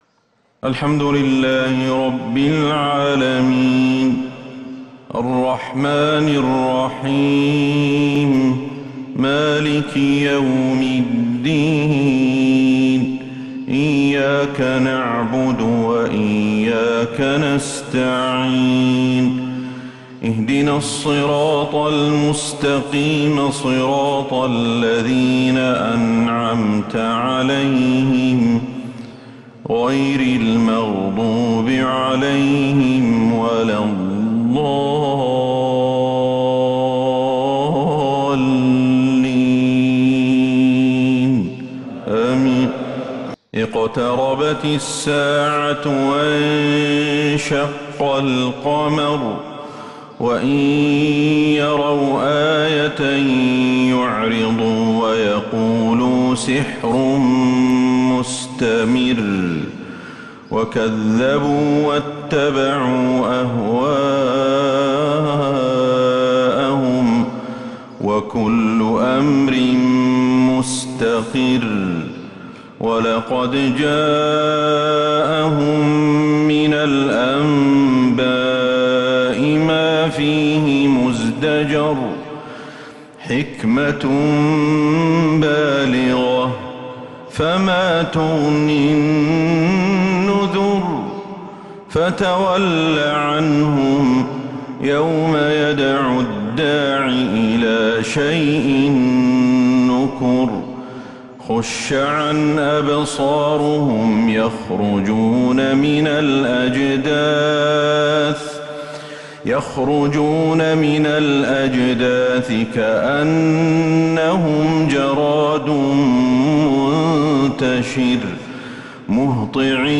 فجر الثلاثاء 7 ربيع الآخر 1444هـ سورة {القمر} كاملة > 1444هـ > الفروض - تلاوات الشيخ أحمد الحذيفي